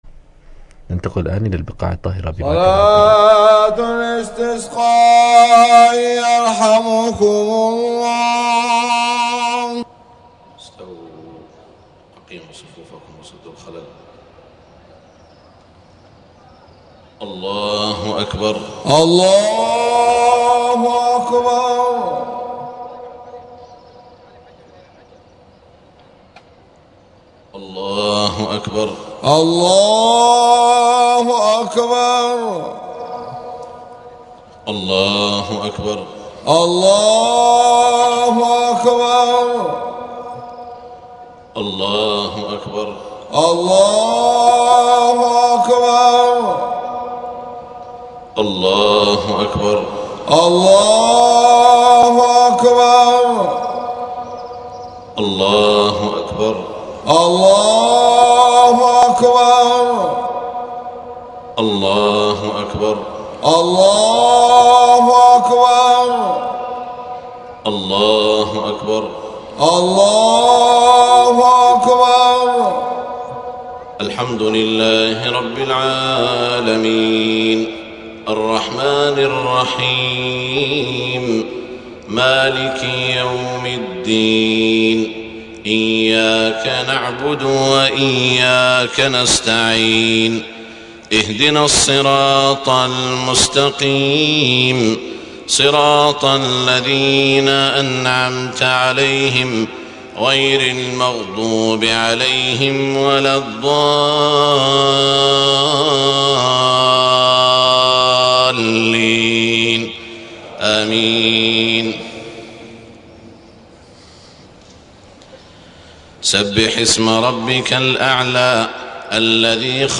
صلاة الاستسقاء 25 محرم 1429 سورتي الأعلى والغاشية > 1429 🕋 > الفروض - تلاوات الحرمين